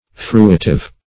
Fruitive \Fru"i*tive\, a.